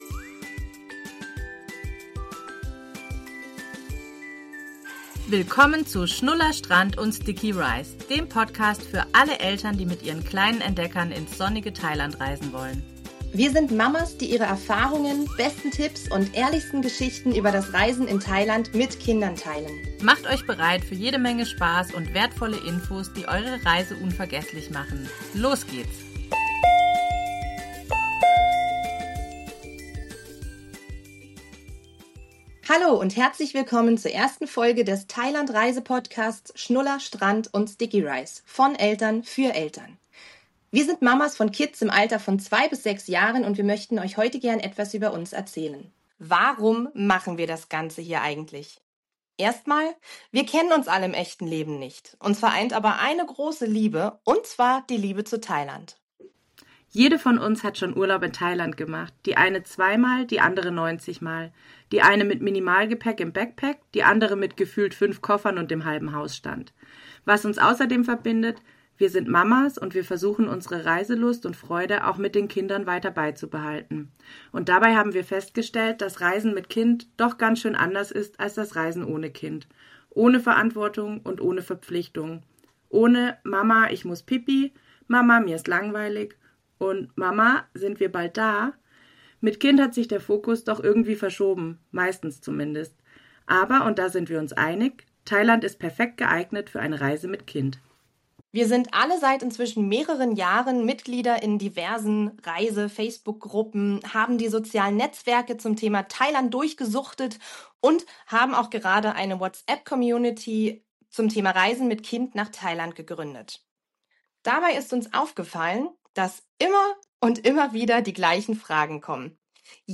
Weitere verwendete Sounds: